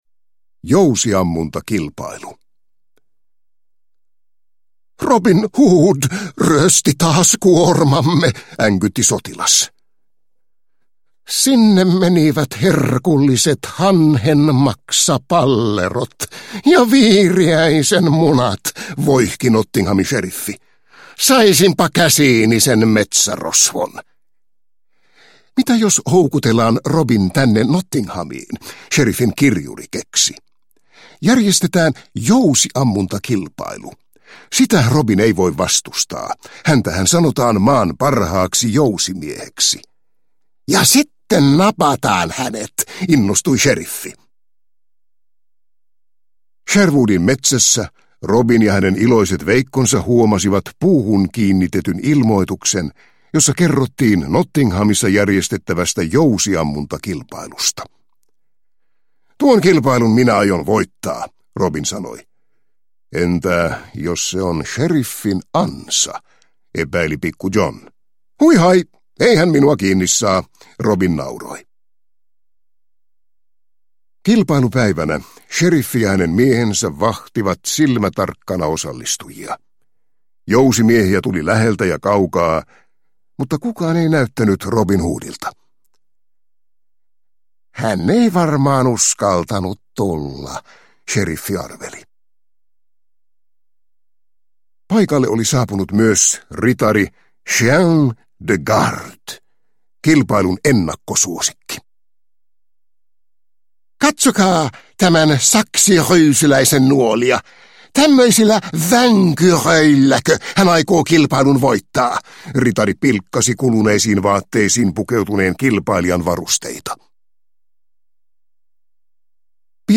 Robin Hood – Ljudbok – Laddas ner